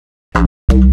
New Bassline Pack